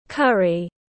Món cà ri tiếng anh gọi là curry, phiên âm tiếng anh đọc là /ˈkʌr.i/
Curry /ˈkʌr.i/